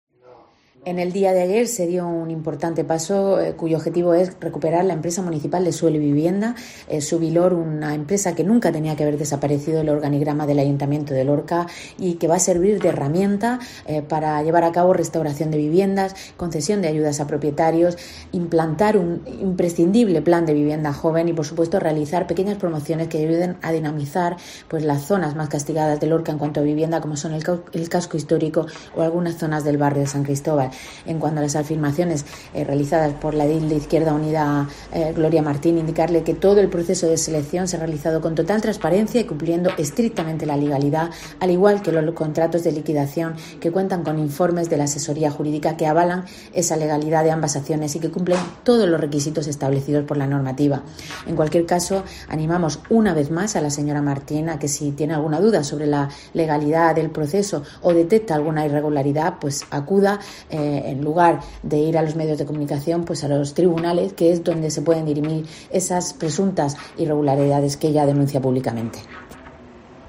Isabel Casalduero, portavoz del equiipo gobierno en Lorca